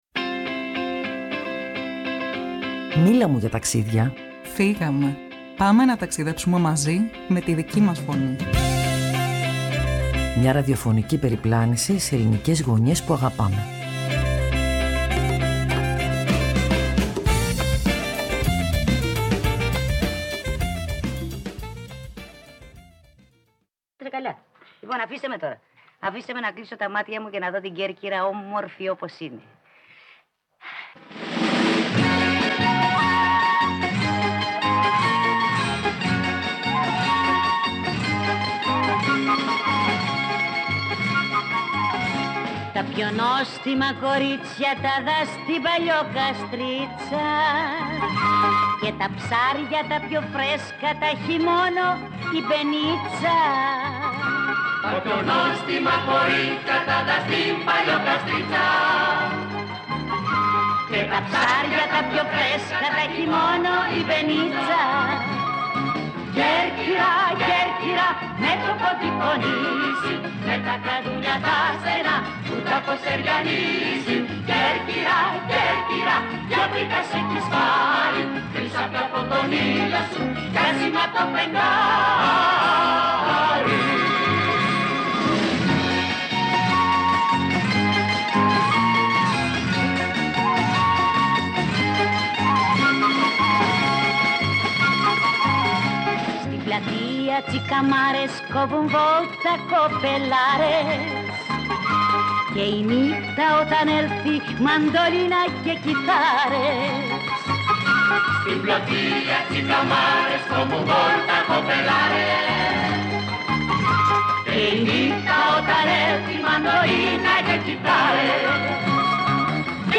Όχι μέσα από τουριστικούς οδηγούς, αλλά μέσα από τις φωνές τριών ανθρώπων που κουβαλούν τον πολιτισμό και την καθημερινότητα του νησιού στην καρδιά τους.